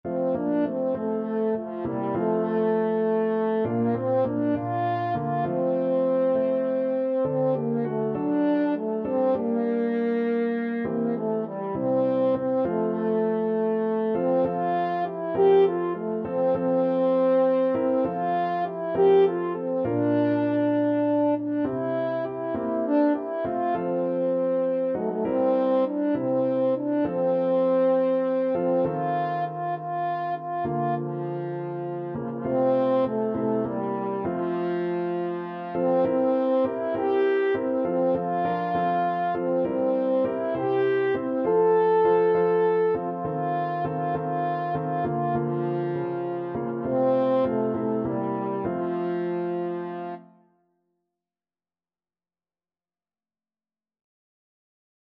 Christian
6/8 (View more 6/8 Music)
Classical (View more Classical French Horn Music)